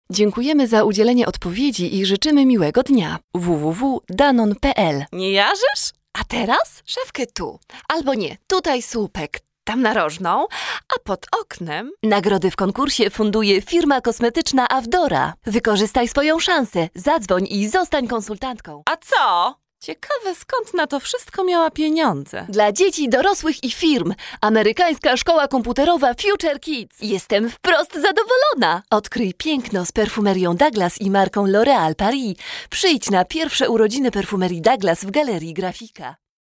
Stimme: jung, fröhlich, dynamisch, warm, klar, freundlich, ruhig, verträumt
Sprechprobe: Industrie (Muttersprache):
polish voice over artist